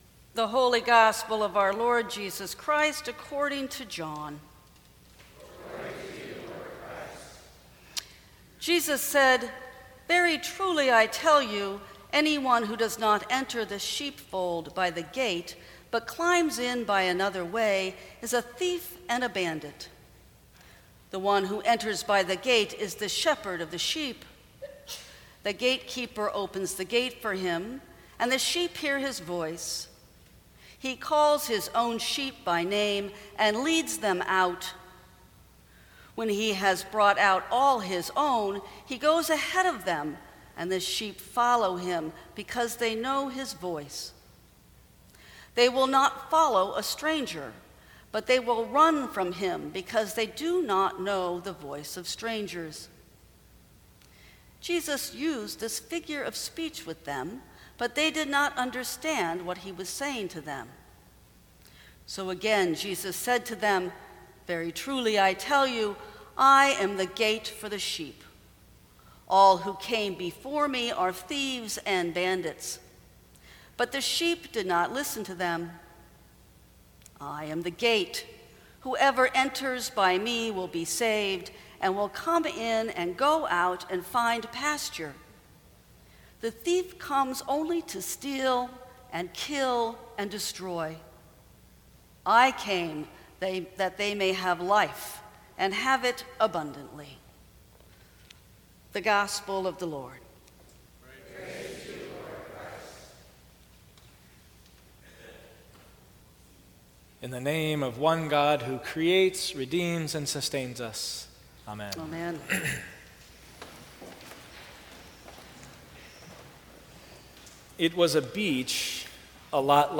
Sermons from St. Cross Episcopal Church The Good Lifeguard May 08 2017 | 00:17:50 1x 00:00 / 00:17:50 Subscribe Share Apple Podcasts Spotify Overcast RSS Feed Share Link Embed